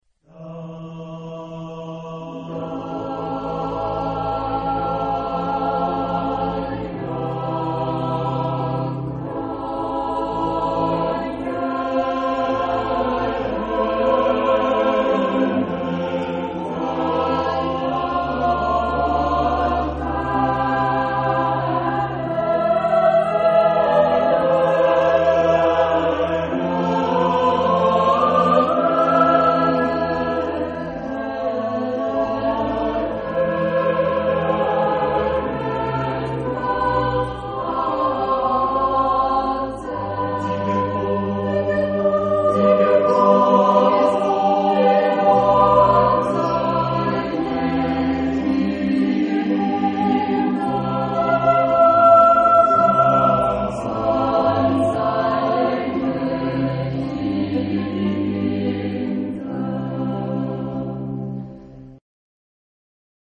Epoque: 17th century  (1600-1649)
Genre-Style-Form: Sacred ; Motet
Type of Choir: SSATB  (5 mixed voices )
Instrumentation: Continuo  (1 instrumental part(s))
sung by Choeur des XVI (CH)